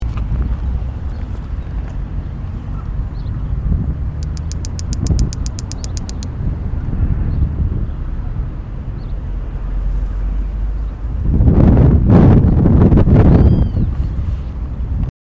Canto. Construyendo nido en un juncal.
Nome em Inglês: Wren-like Rushbird
Detalhada localização: Área Protegida Municipal Laguna Cacique Chiquichano
Condição: Selvagem
Junquero--canto.mp3